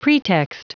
Prononciation du mot pretext en anglais (fichier audio)
Prononciation du mot : pretext